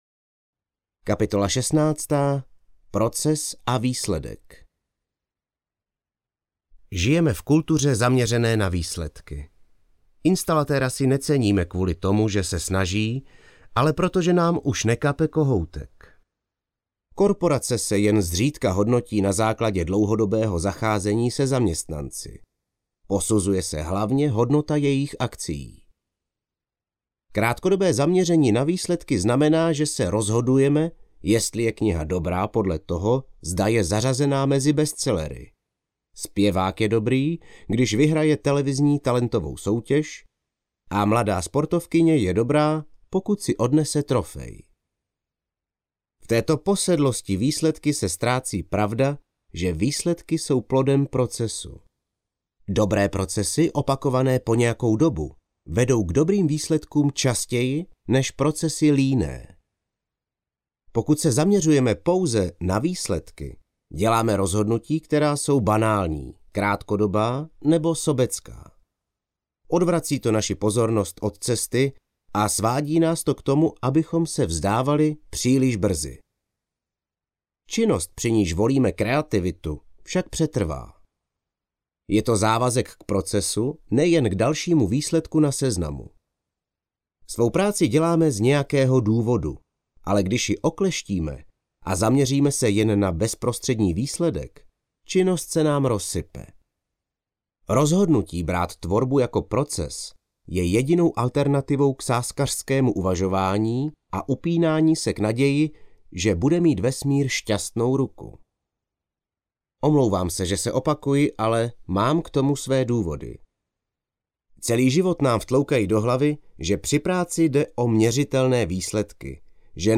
Proces audiokniha
Ukázka z knihy